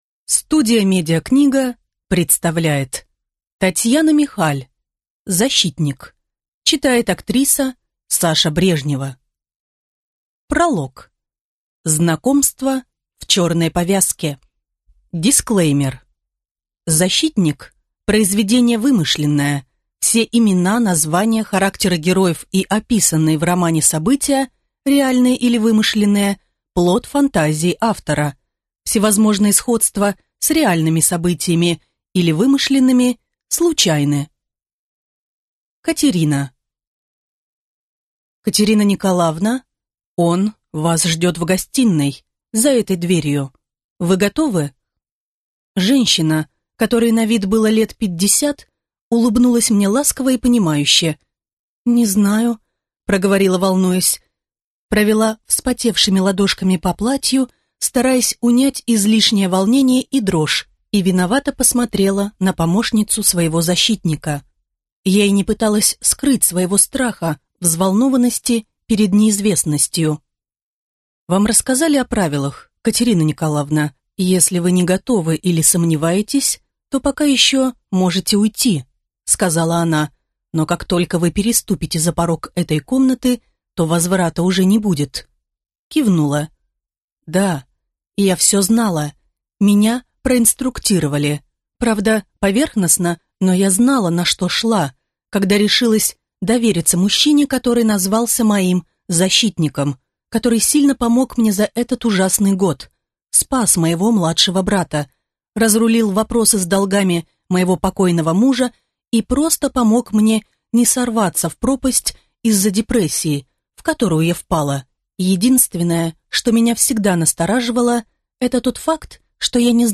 Aудиокнига
Прослушать и бесплатно скачать фрагмент аудиокниги